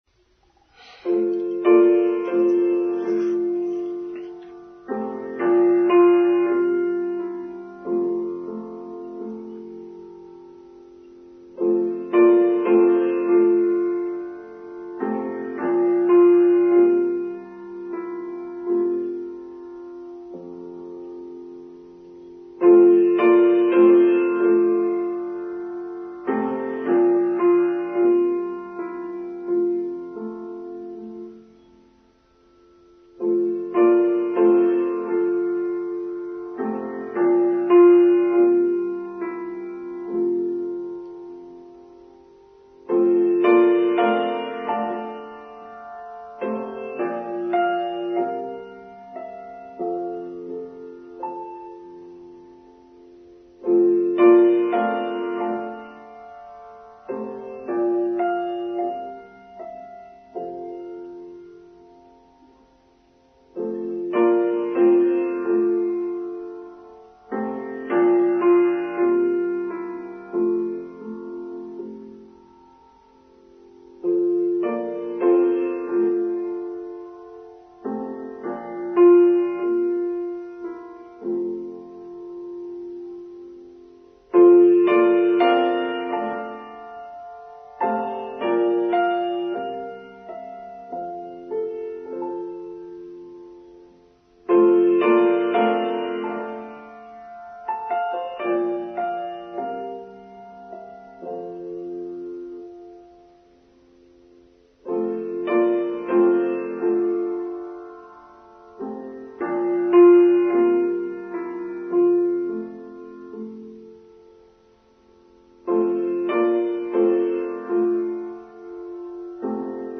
Music of the Heart: Online Service for Sunday 20th November 2022